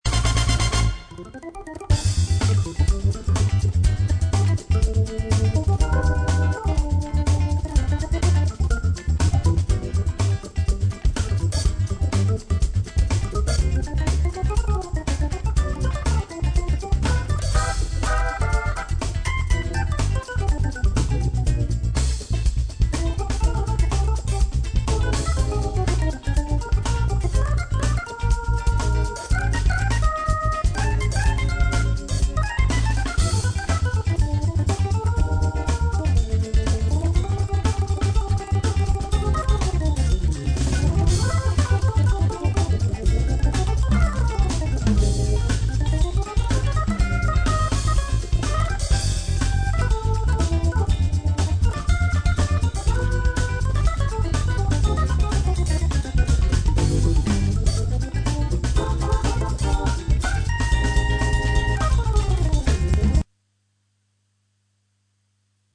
Quel est donc l’organiste qui se démène sur ce rythme endiablé ?
funk3.mp3